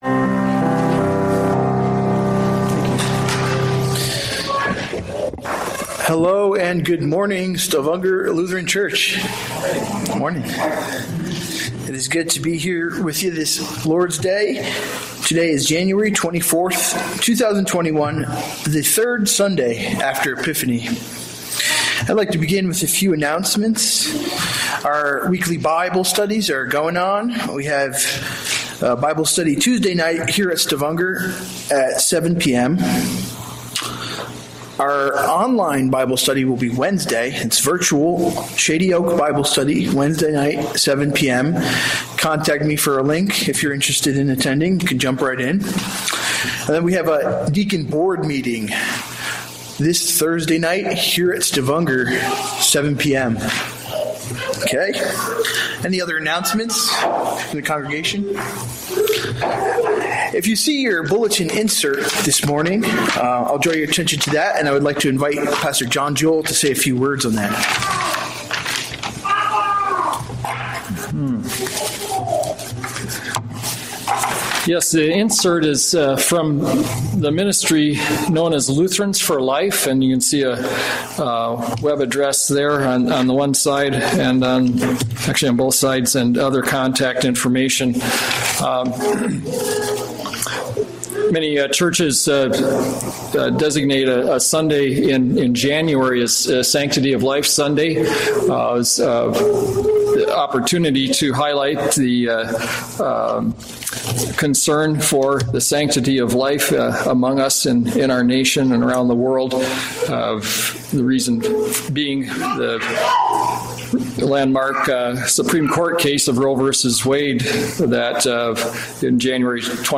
A message from the series "Sunday Worship."
From Series: "Sunday Worship"